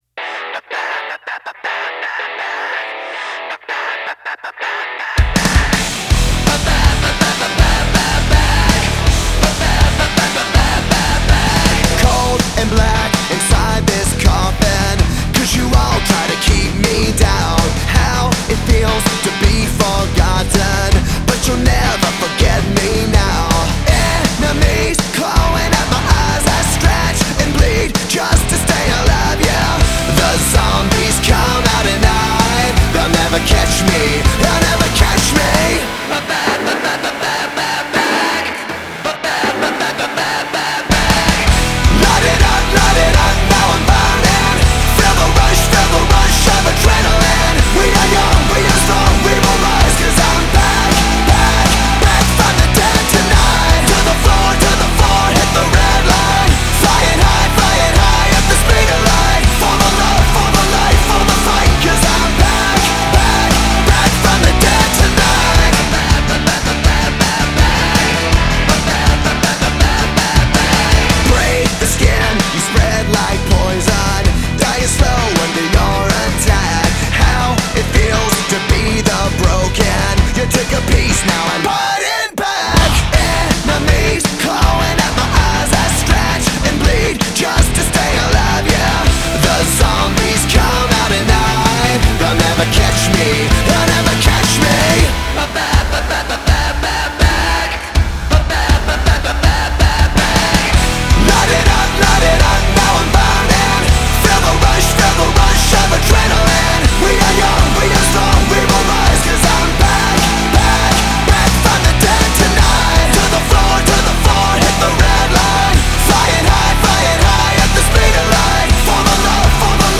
Hard Rock
rock music